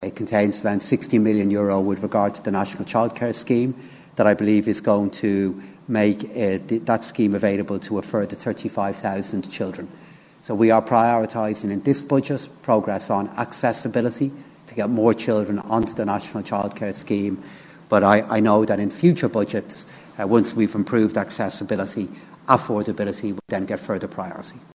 Finance Minister Paschal Donohoe says they can’t afford to do everything in one budget……..